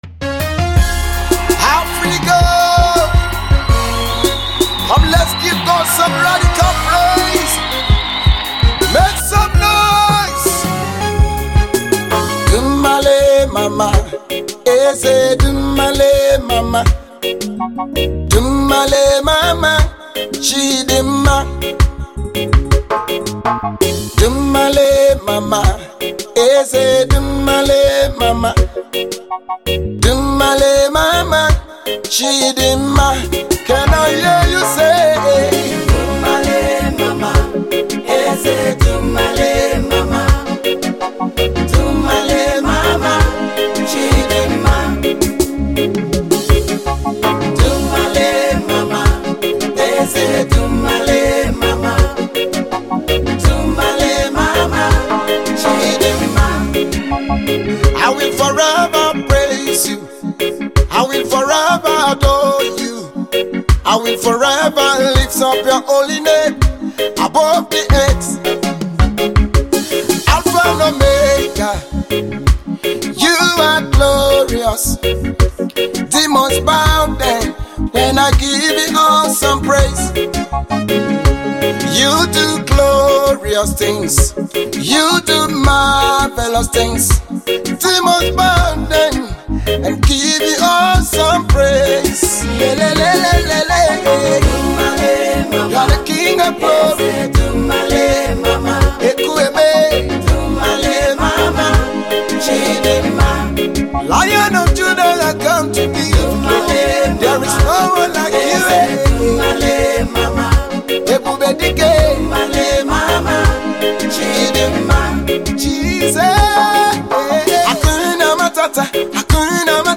its form part of Igbo most worship song.